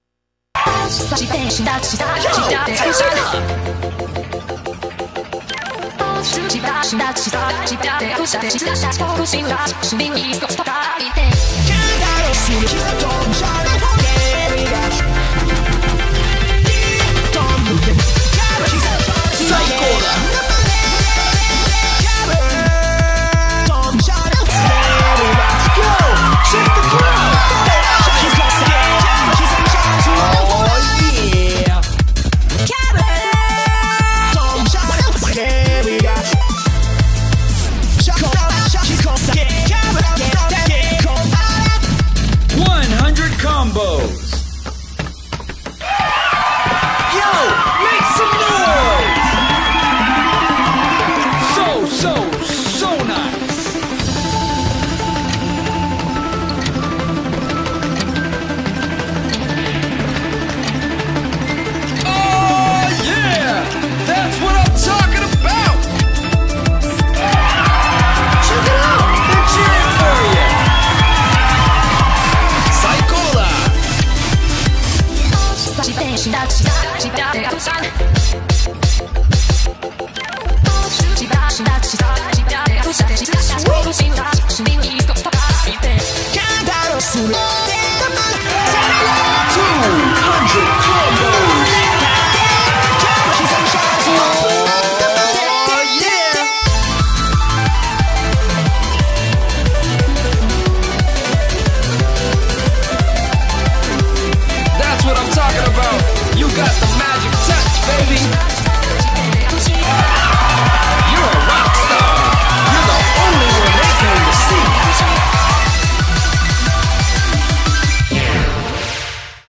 BPM180
MP3 QualityLine Out